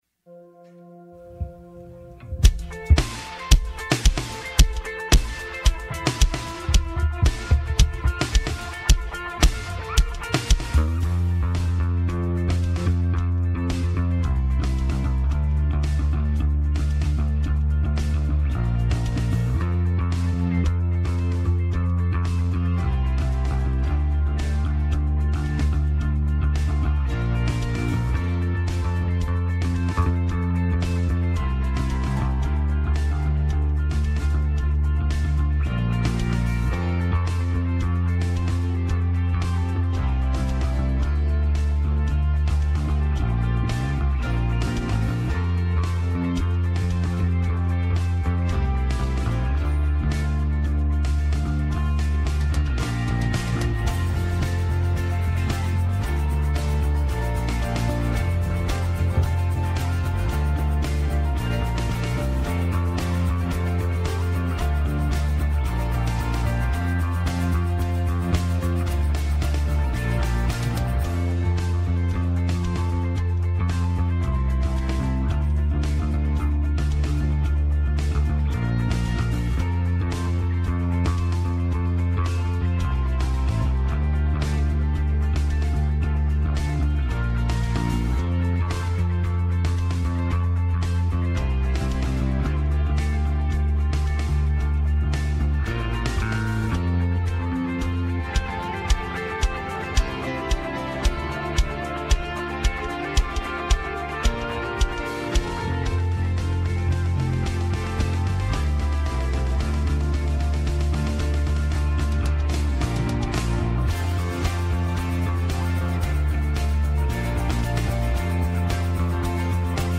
Westgate Chapel Sermons Sanctity of Human Life Sunday Mar 02 2025 | 01:29:22 1x 00:00 / 01:29:22 Subscribe Share Apple Podcasts Overcast RSS Feed Share Link Embed